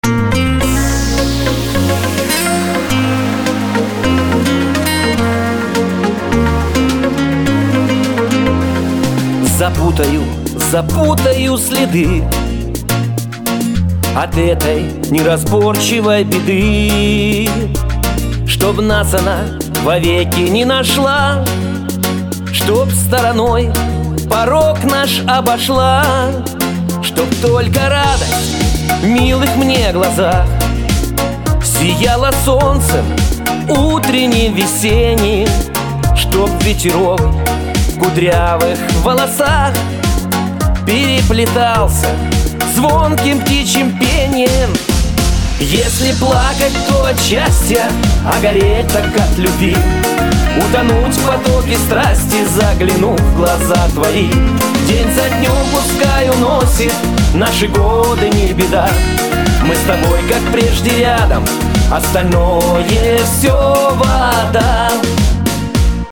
• Качество: 224, Stereo
гитара
душевные
красивый мужской голос
спокойные
романтичные
русский шансон